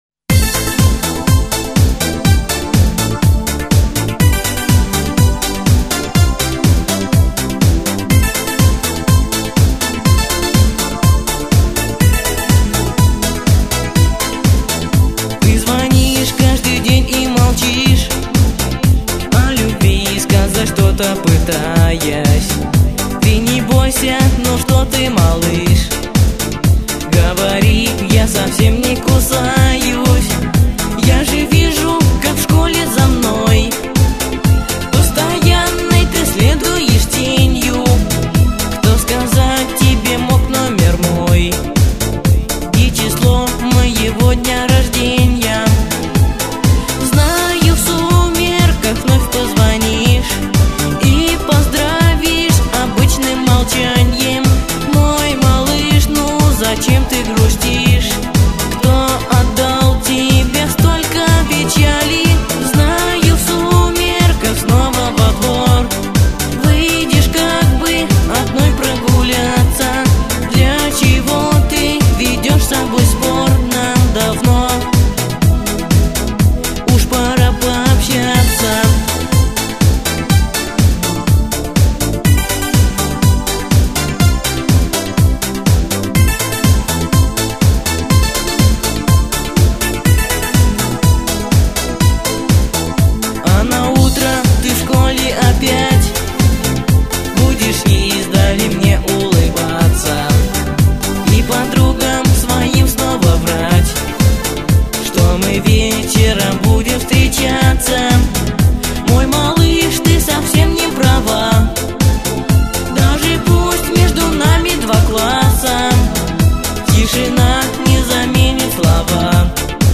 вокал